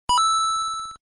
Звуки 8 бит
Звук СМС из игры Денди Супер Марио монетка